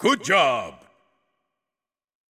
goodjob.ogg